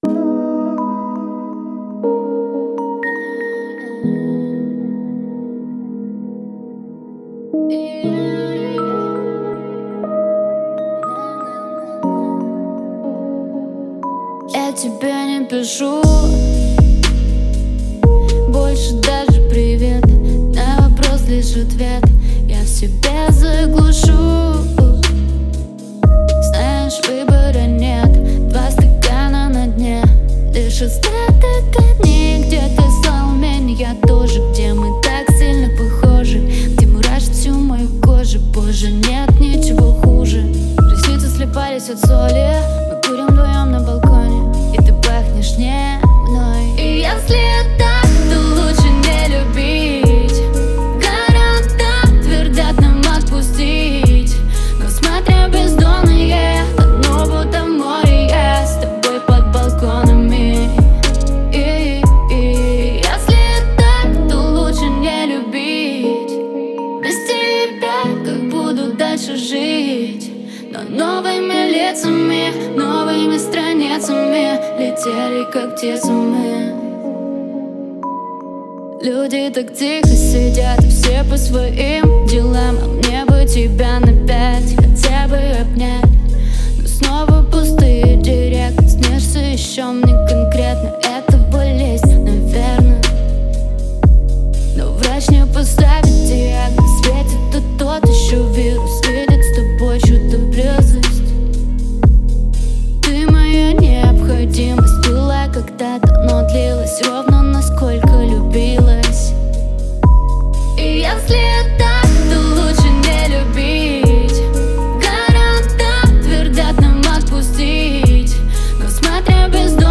Классная грустная песня под дождем идет )